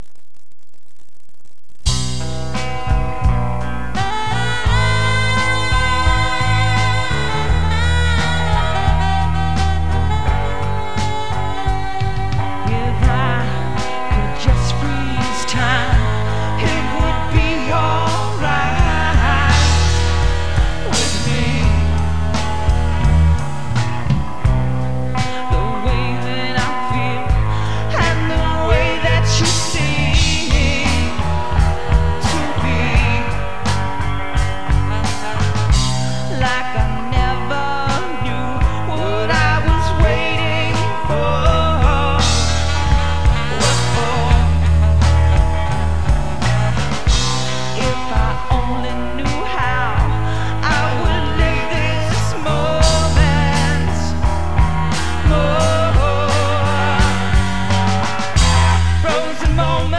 special moment where you feel great